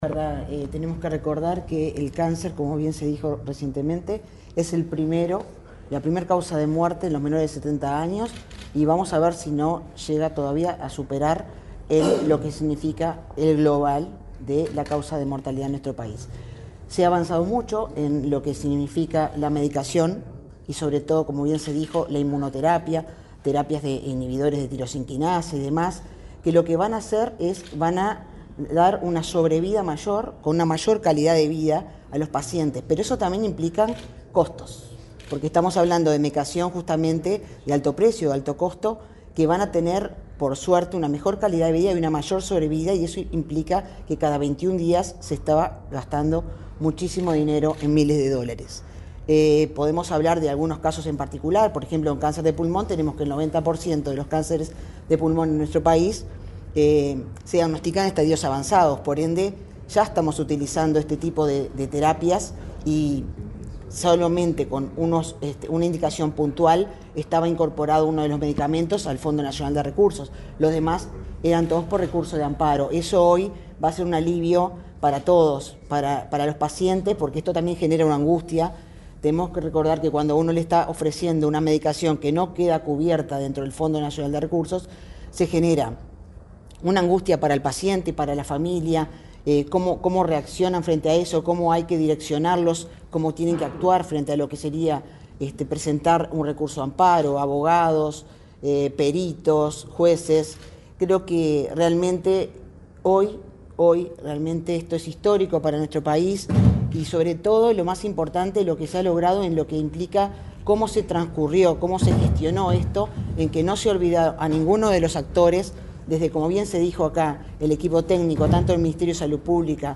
Declaraciones de la directora del Programa Nacional de Control del Cáncer del MSP, Marisa Fazzino
Declaraciones de la directora del Programa Nacional de Control del Cáncer del MSP, Marisa Fazzino 10/09/2024 Compartir Facebook X Copiar enlace WhatsApp LinkedIn El Ministerio de Salud Pública (MSP) anunció la incorporación de nuevos medicamentos a las prestaciones del Fondo Nacional de Recursos (FNR). Luego del acto, la directora del Programa Nacional de Control del Cáncer del MSP, Marisa Fazzino, explicó a la prensa, el alcance de la medida.